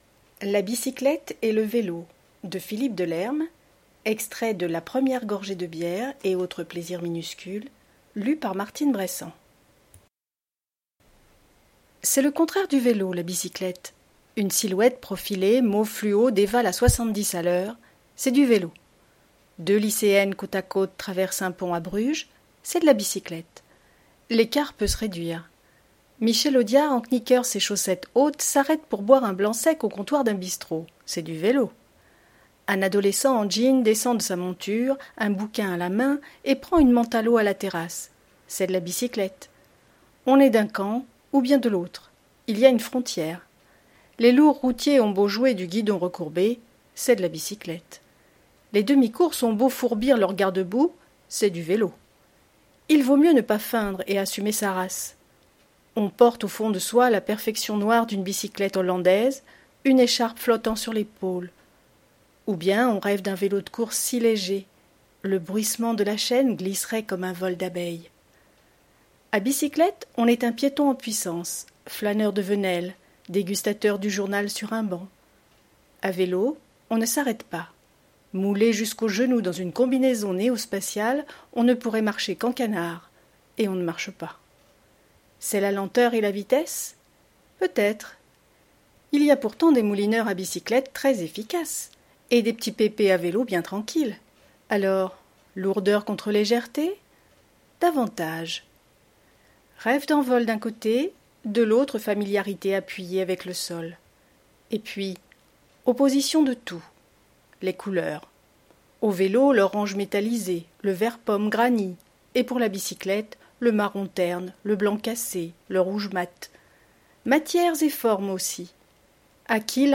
Lecture à haute voix - La bicyclette et le vélo